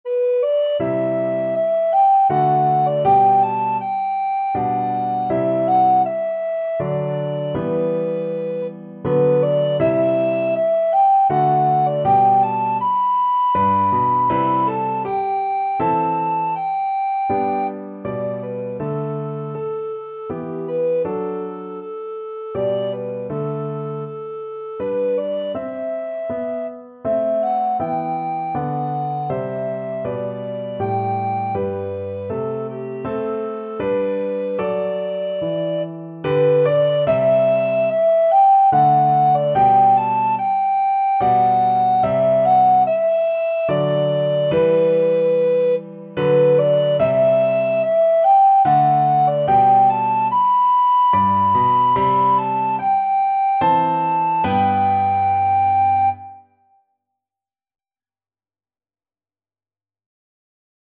Alto Recorder
3/4 (View more 3/4 Music)
G major (Sounding Pitch) (View more G major Music for Alto Recorder )
Andante maestoso = c.80
Classical (View more Classical Alto Recorder Music)